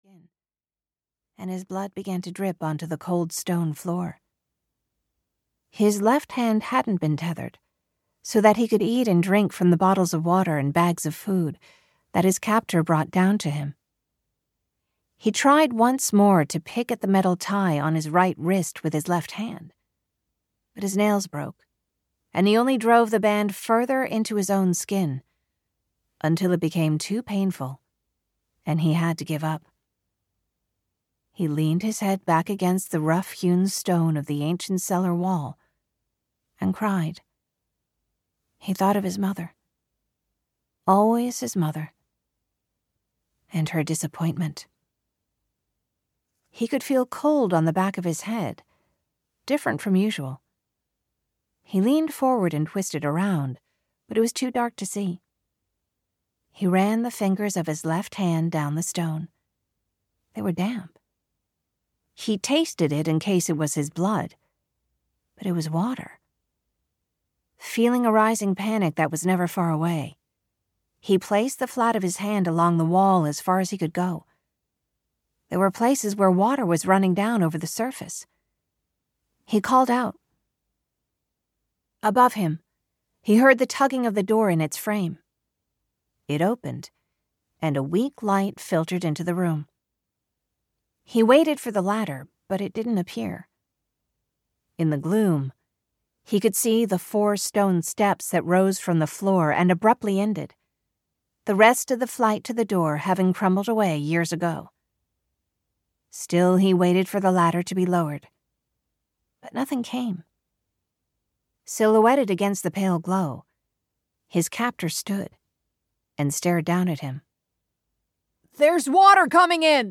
City of Drowned Souls (EN) audiokniha
Ukázka z knihy